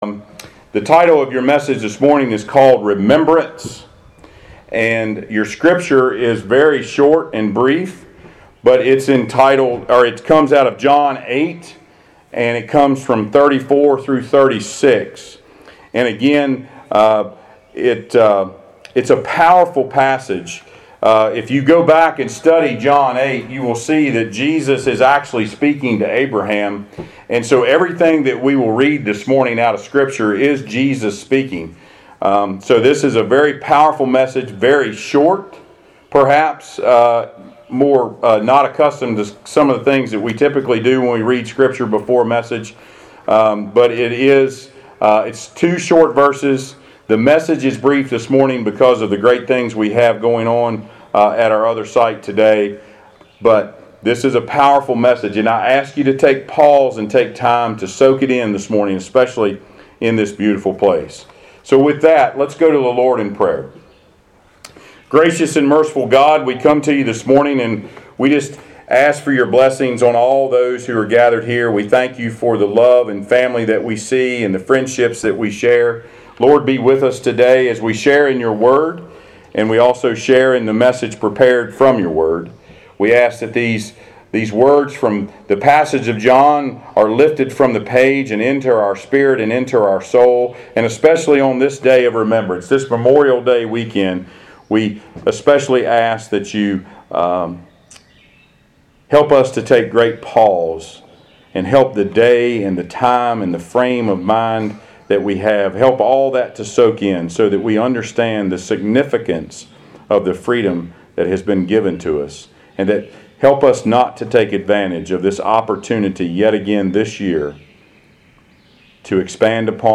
Passage: John 8:34-36 Service Type: Sunday Worship